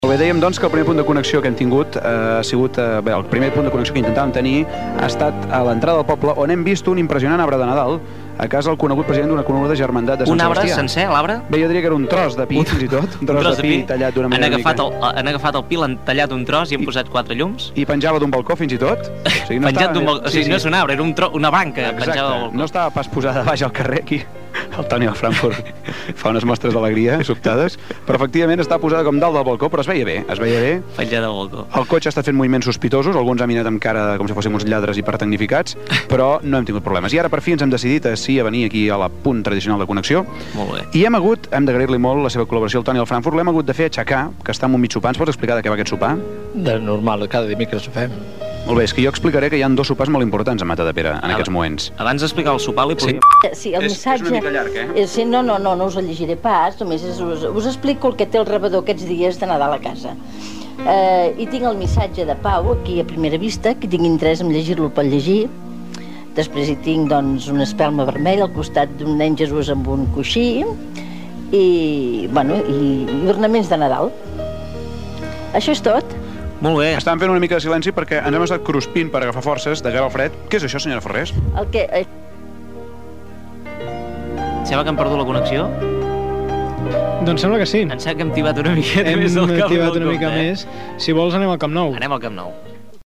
Connexió amb la unitat mòbil.
Entreteniment